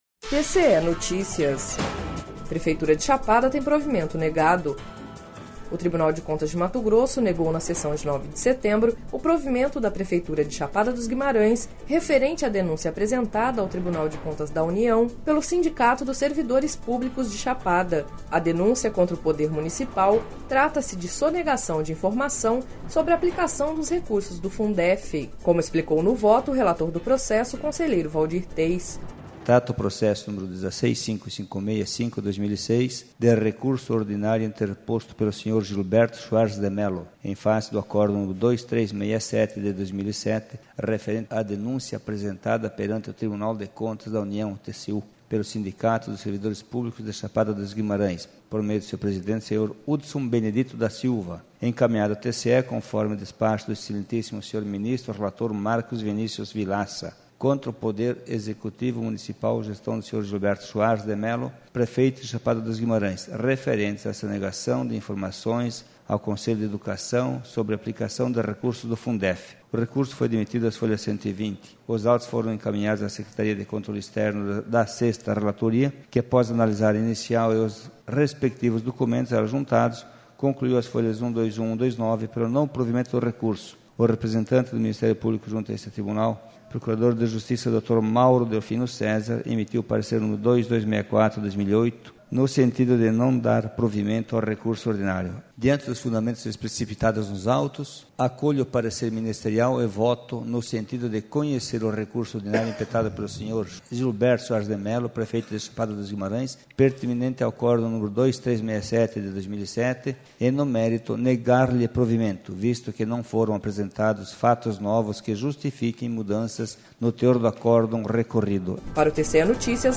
Sonora: Waldir Teis - conselheiro presidente do TCE-MT